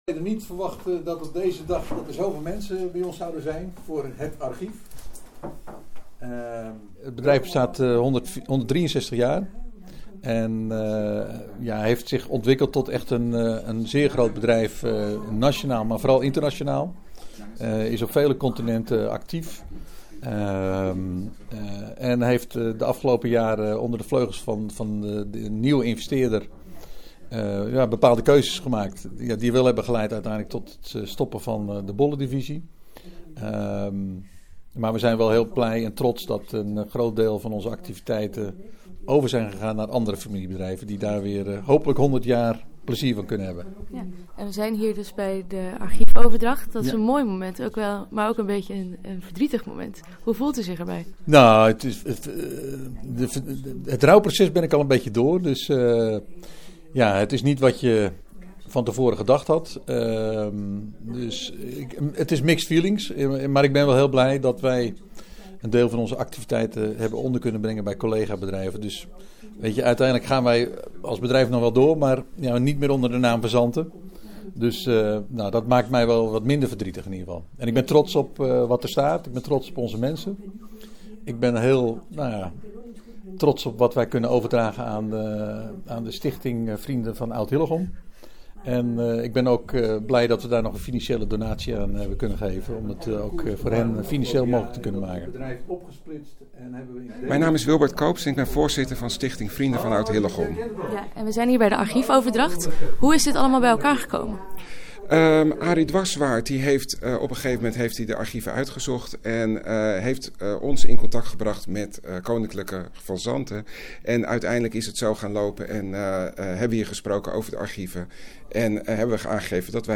Audioreportage